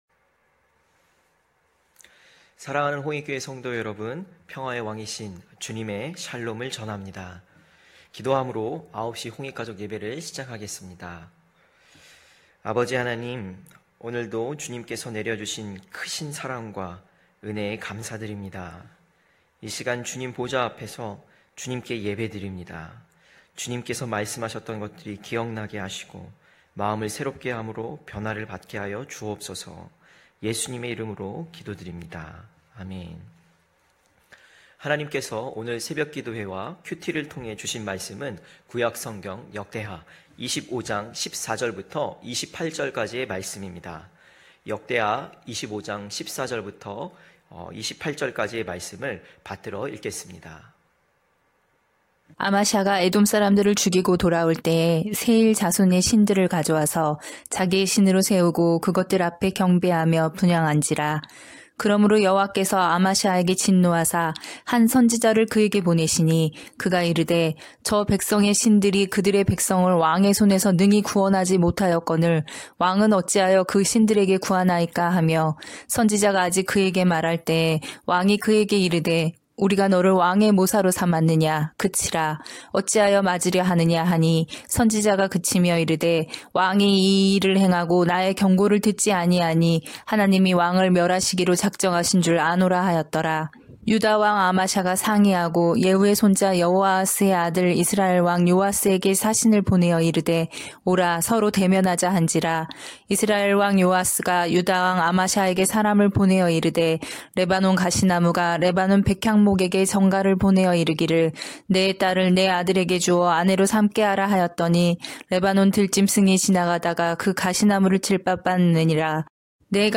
9시홍익가족예배(12월4일).mp3